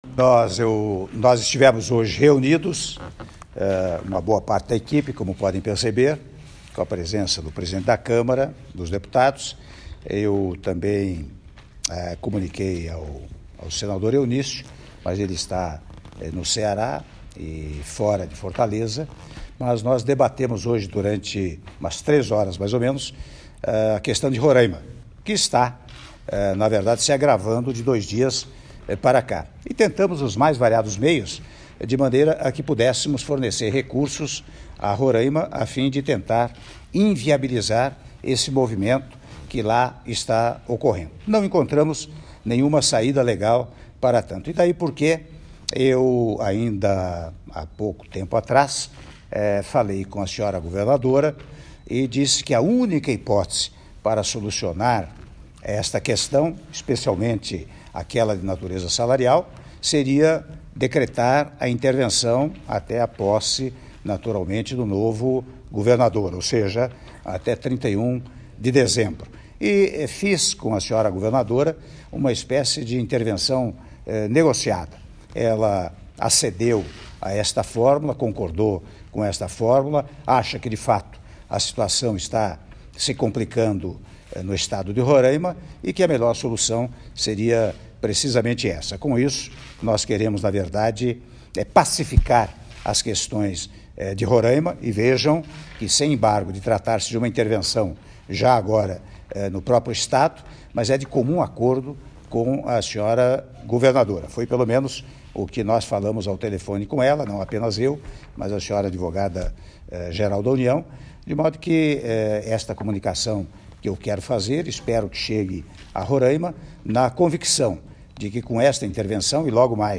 Áudio da declaração do Presidente da República, Michel Temer, após reunião com Ministros no Palácio da Alvorada - (03min02s)